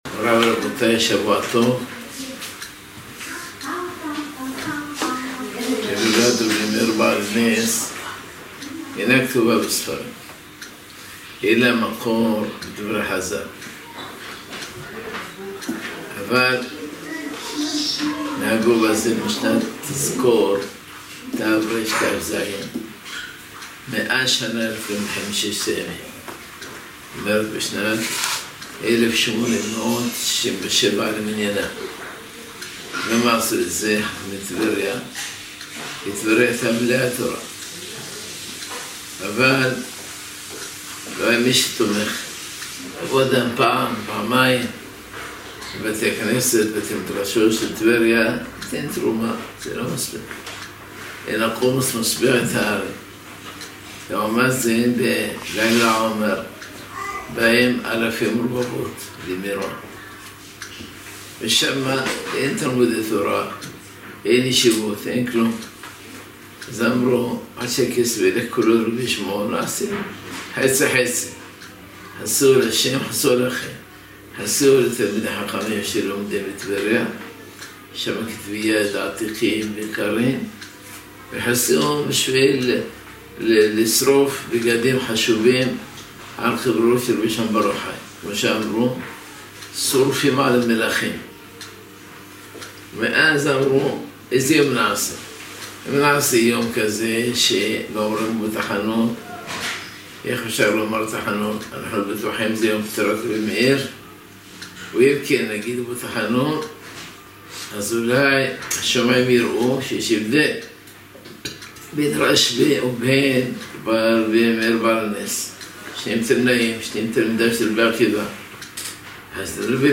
שיעור מיוחד ממרן שליט"א: הילולת רבי מאיר בעל הנס